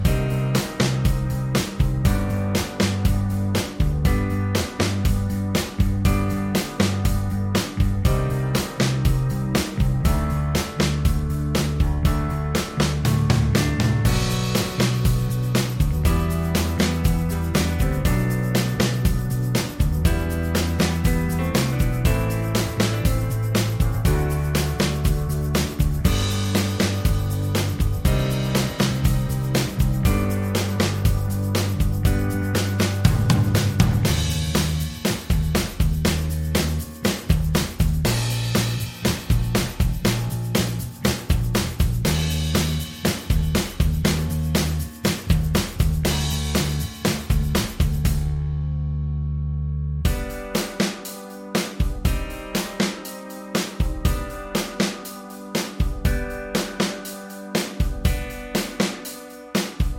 Minus Main Guitars For Guitarists 2:27 Buy £1.50